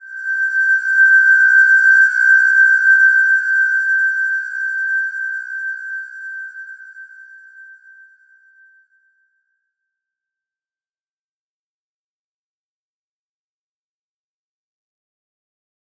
Slow-Distant-Chime-G6-f.wav